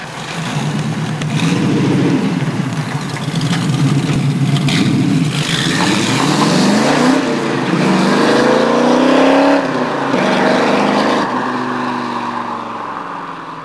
Sons de moteurs tvr - Engine sounds tvr - bruit V8 V10 tvr
Griffith 500 - acceleration.wav